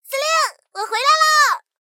SU-76战斗返回语音.OGG